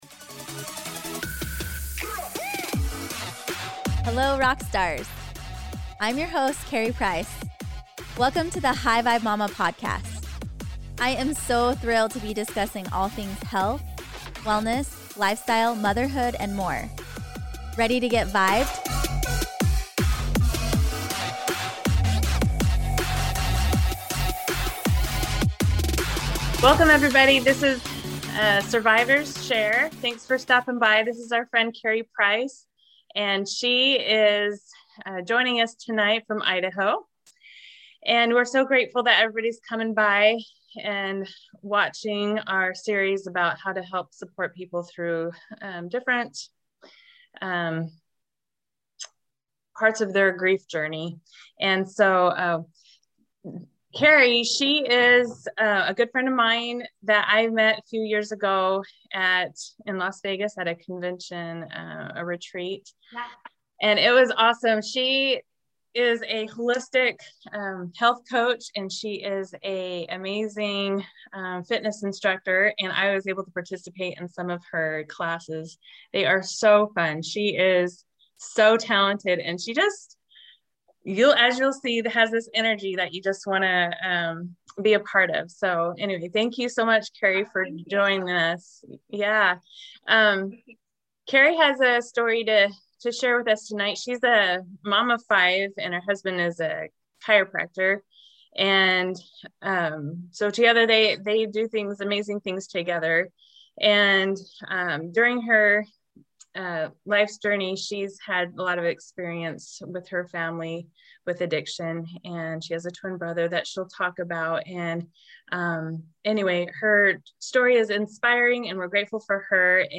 #23 INTERVIEW WITH SRVIVRS SHARE NETWORK (Grief & Loss)
Yes, I was nervous, as you can tell in my voice.....also some of my sentences probably don't make sense...LOL. However I hope you feel the message of HOPE.